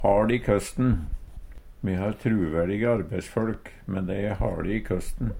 DIALEKTORD PÅ NORMERT NORSK haL i køsten nokon som et mykje Eksempel på bruk Me har truverdige arbeisfølk, men dei era haLe i køsten.